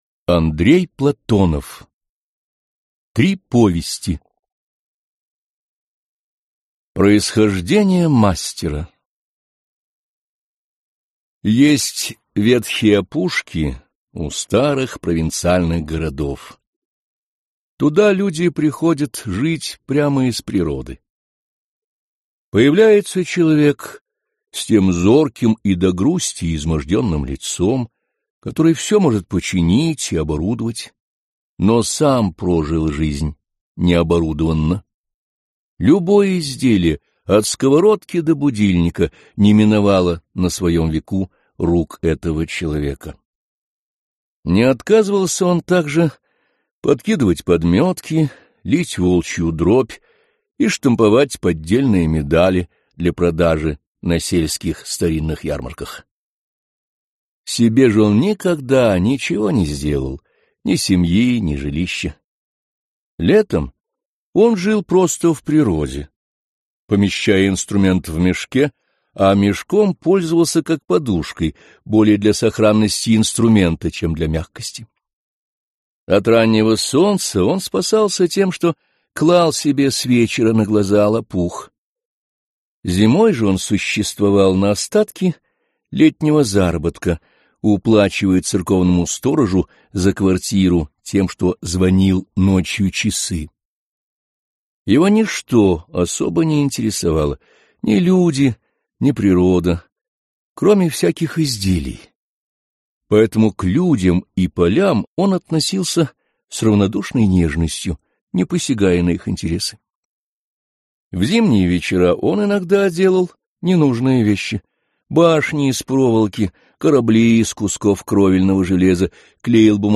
Аудиокнига Три повести | Библиотека аудиокниг